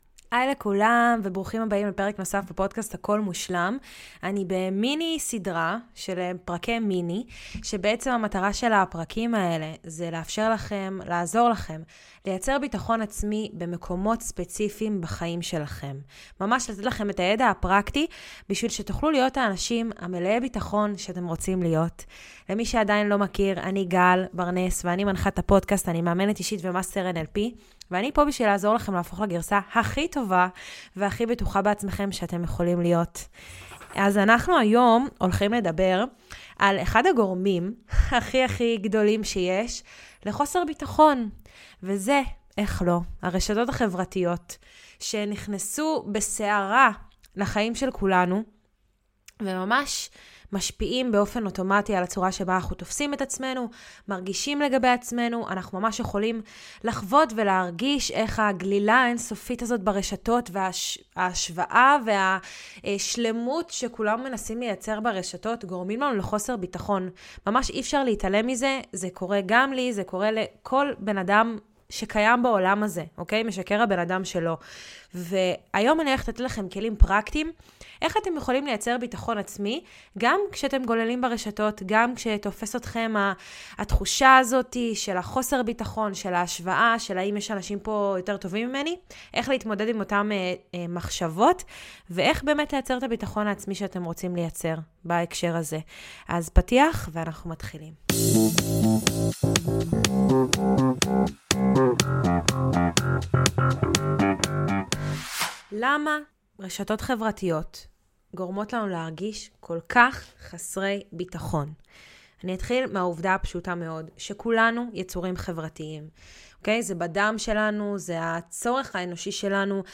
מיני עונה של פרקי סולו קצרים וממוקדים שיעזרו לכם לפתח ביטחון עצמי במקומות ספציפיים בחייכם!